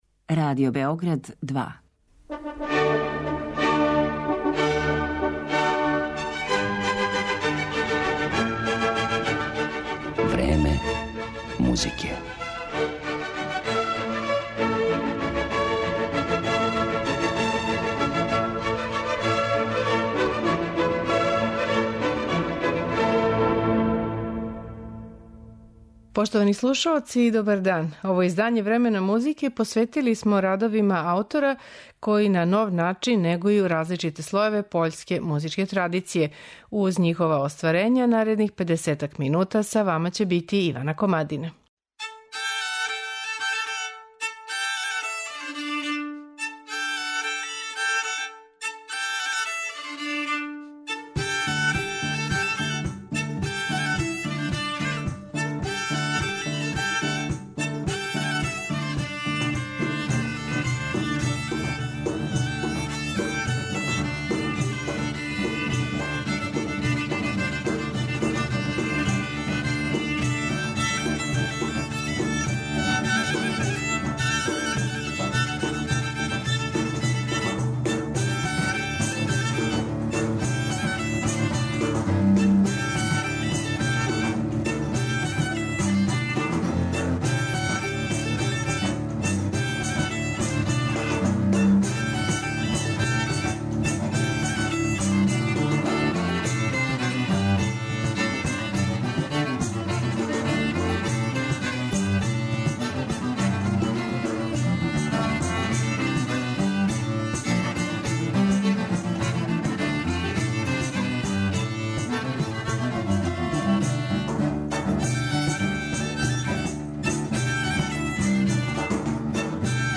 У данашњем Времену музике представићемо радове аутора који се ослањају на пољску фолклорну традицију и у њој налазе музички материјал који ће креативно преобликовати.